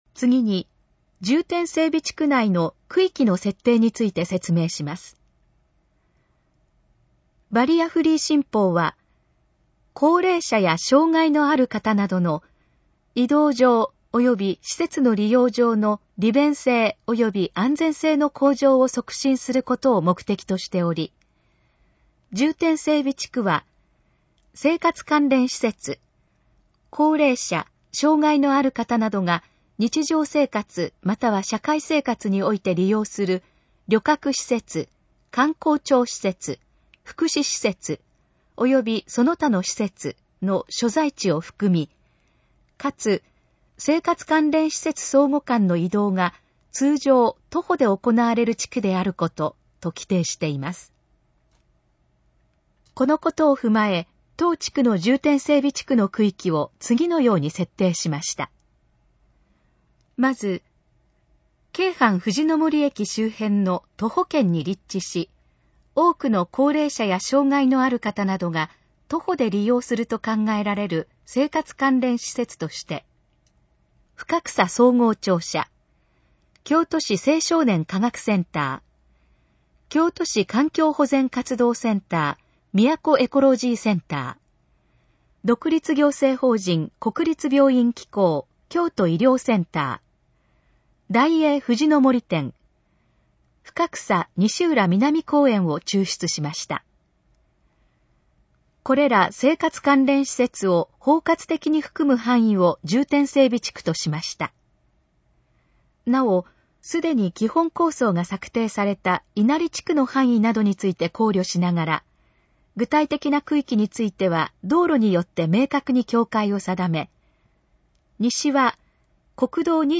このページの要約を音声で読み上げます。
ナレーション再生 約584KB